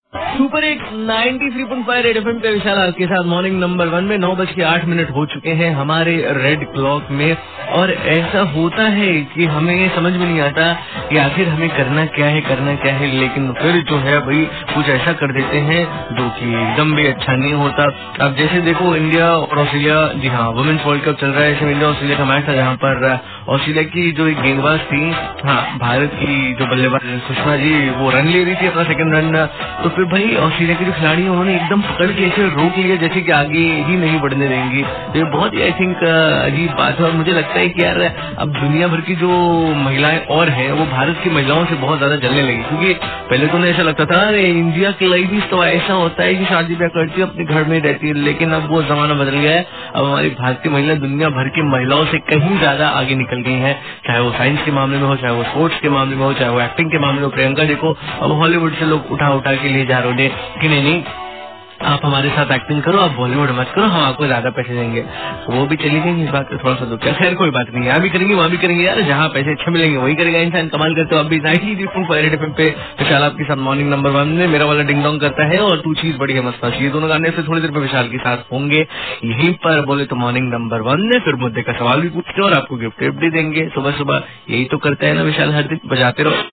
RJ TALKING ABOUT WOMEN CRICKET MATCH